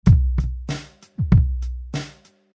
嘻哈大鼓1
嘻哈说唱原声鼓鼓点
Tag: 96 bpm Hip Hop Loops Drum Loops 430.90 KB wav Key : Unknown